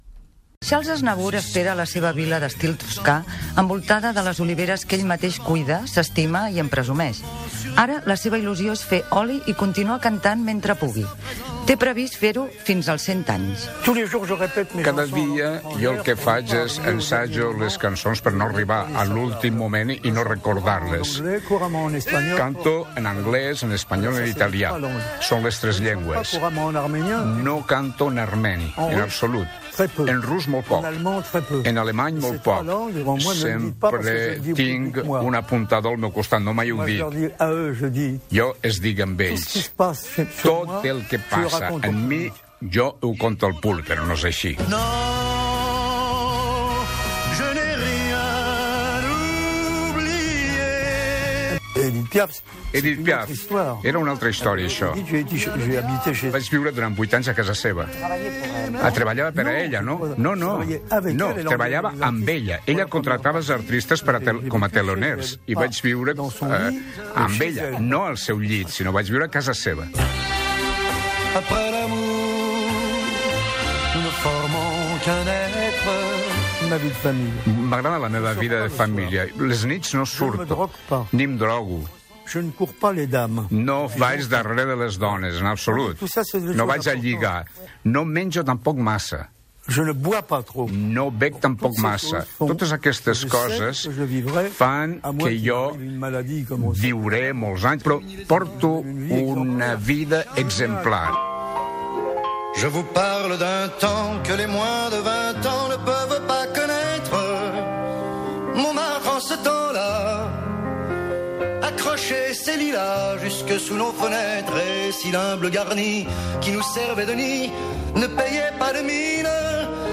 Entrevista al cantant Charles Aznavour (Shahnour Vaghenag Aznavourian), feta a la seva vila de la Proveça